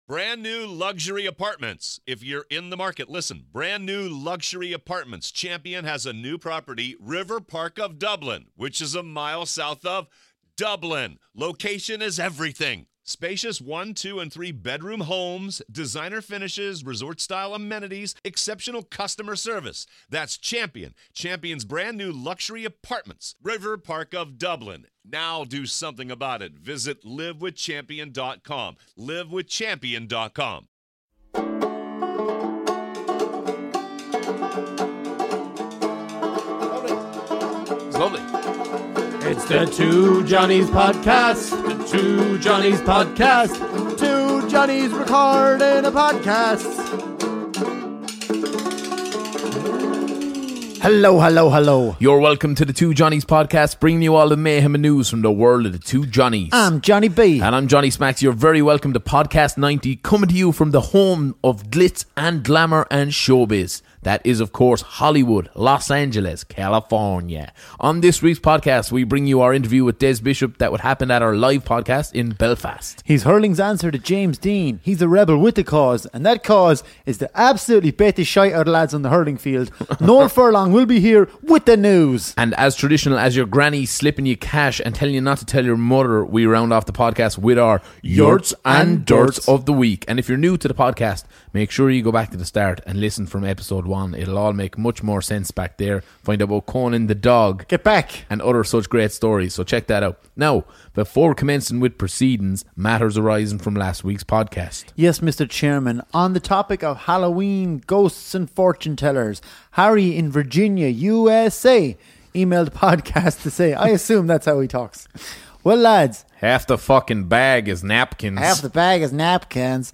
Ireland's favourite comedy duo tackle the big issues, This week:
Also, hear our interview with Irish American comedian Des Bishop, Live in Belfast.